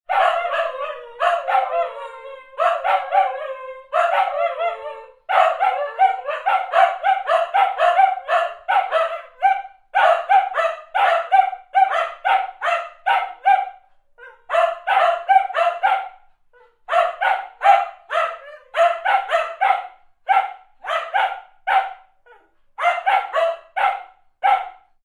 Dog Bark Sounds ringtone free download
Animals sounds